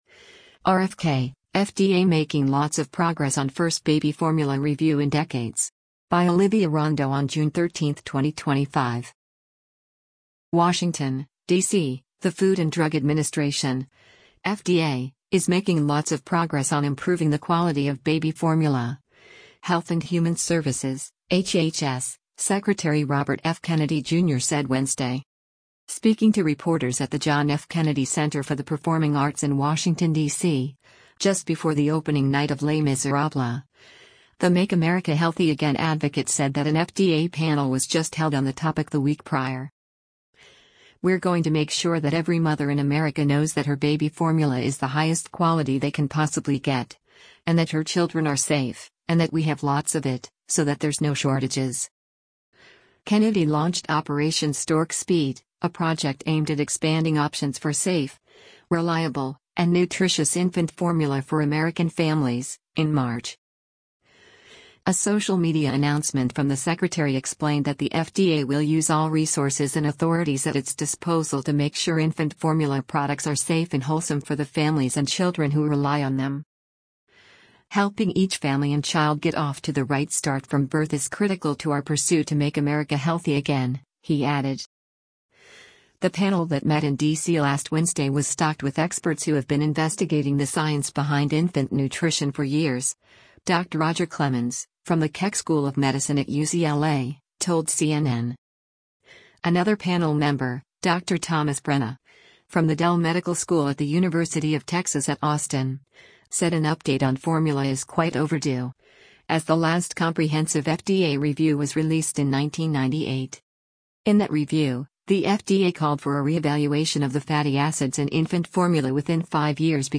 Speaking to reporters at the John F. Kennedy Center for the Performing Arts in Washington, DC, just before the opening night of Les Misérables, the “Make America Healthy Again” advocate said that an FDA panel was just held on the topic the week prior.